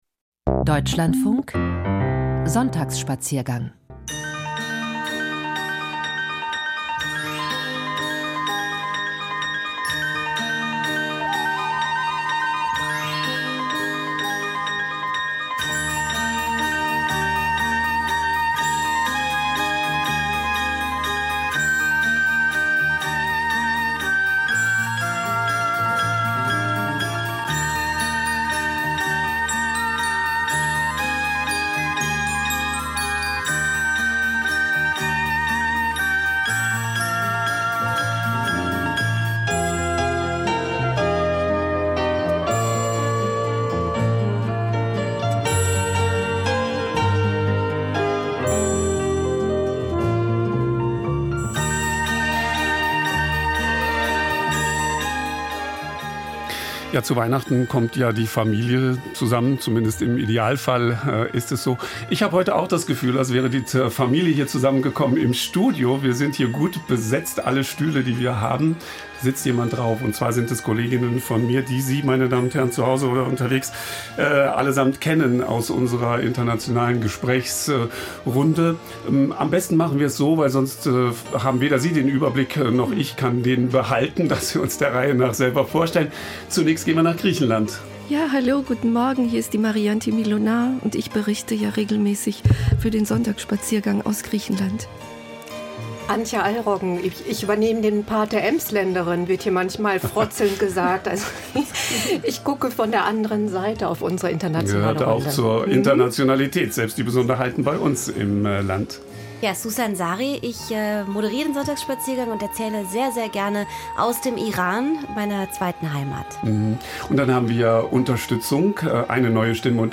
Internationale Gesprächsrunde - Verständigung durch Reisejournalismus